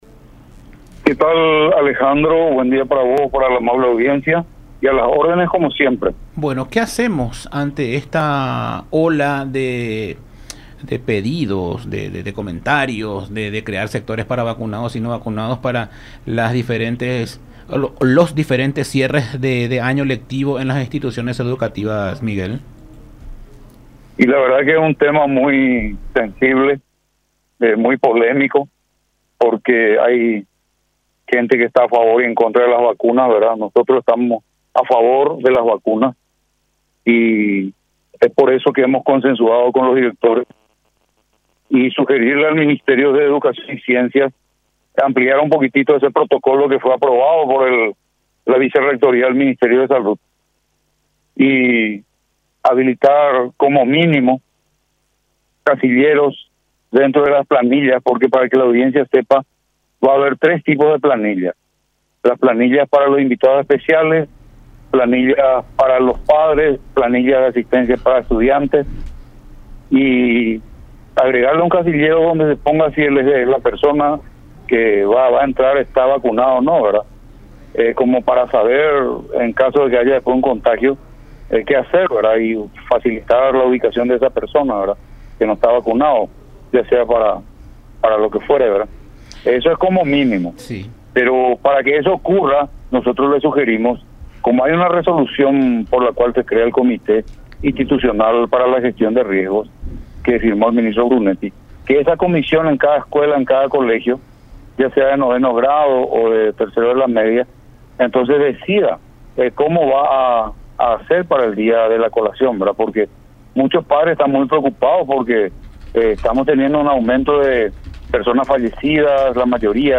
en diálogo con Todas Las Voces por La Unión